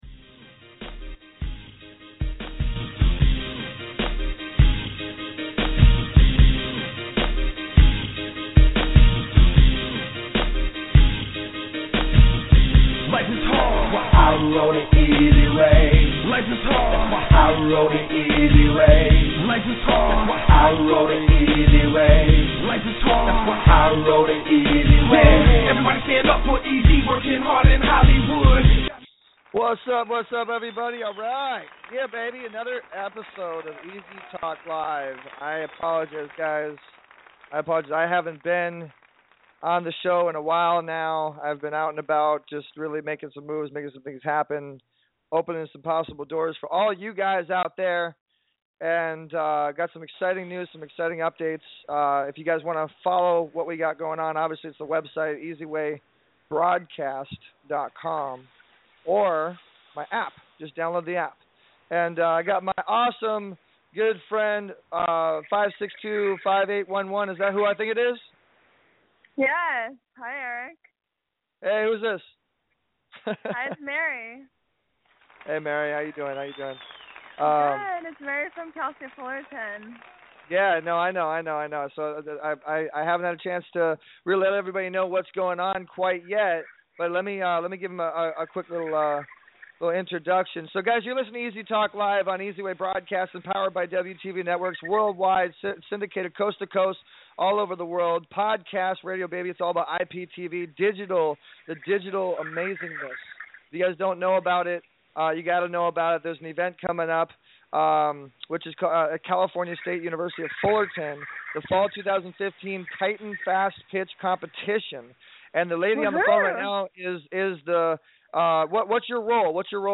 EZ TALK LIVE With Co-Host Judge Joe Brown